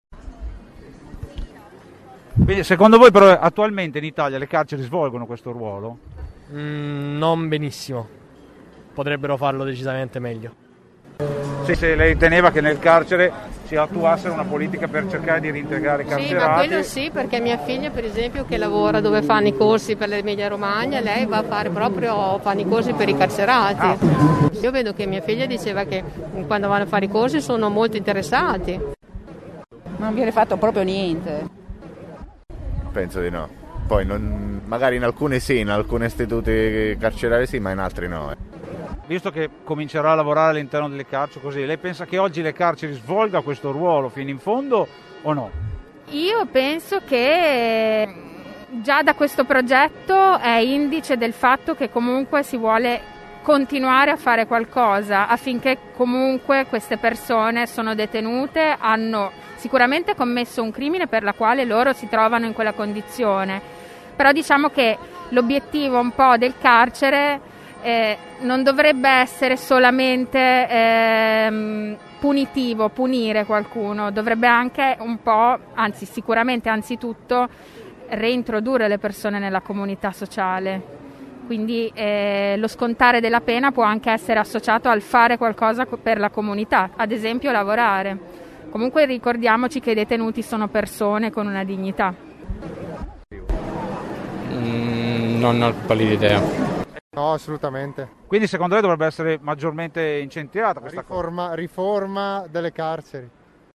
Duranti i numerosi interventi che si sono susseguite con anche collegamenti esterni, (vedi “Senza Quartiere” ), noi abbiamo provato a chiedere ai cittadini presenti, il loro parere rispetto a questo argomento.